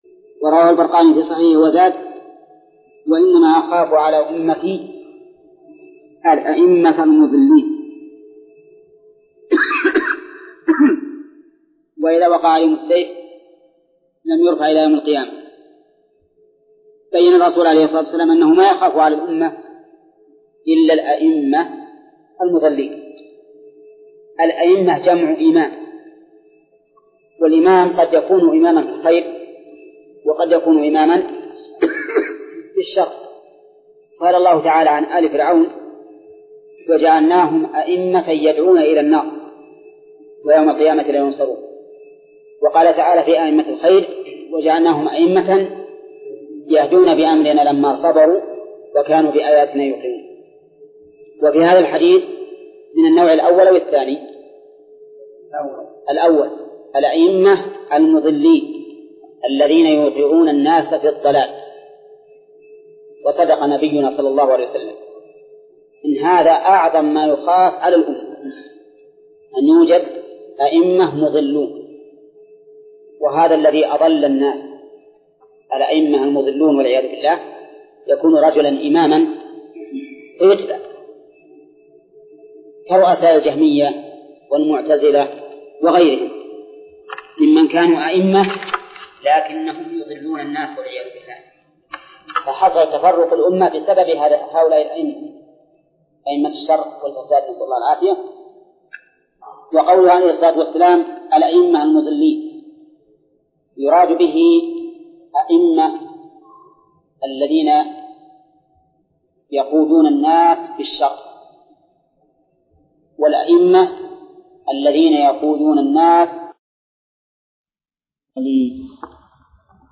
درس (23) : من صفحة: (477)، قوله: (وروى البرقاني..). إلى : صفحة: (494)، قوله: (وعن أبي هريرة:..)